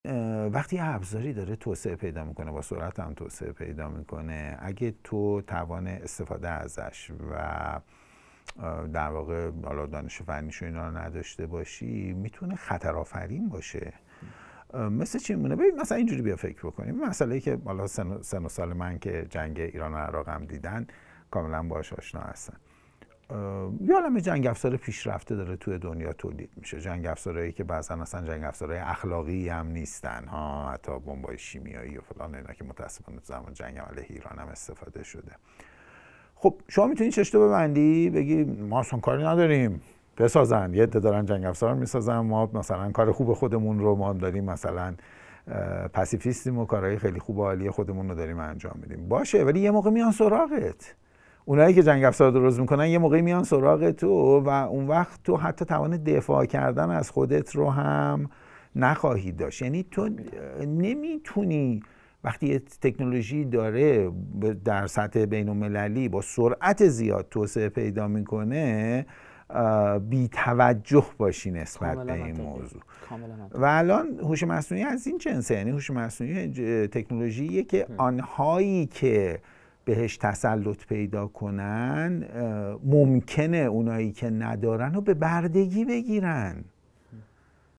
در ادامه، بخش بسیار کوتاه و جذابی از صدای مصاحبه با استاد آمده است.